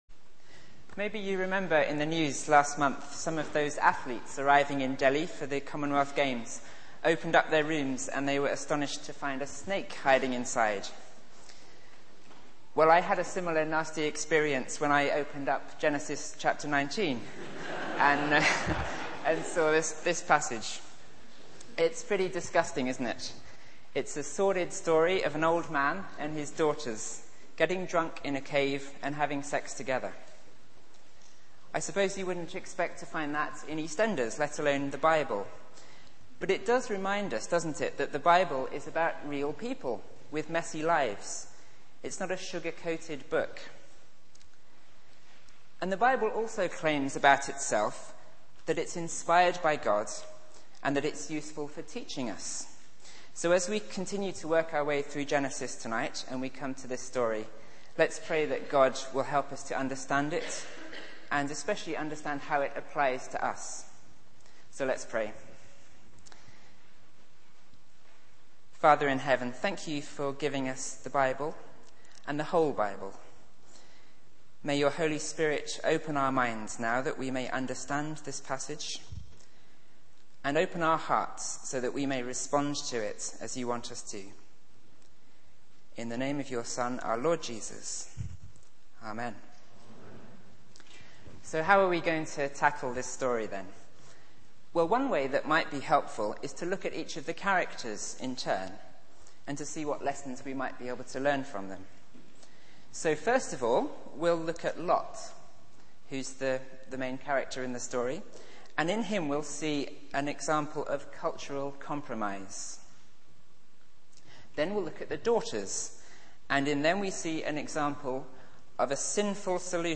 Media for 6:30pm Service on Sun 31st Oct 2010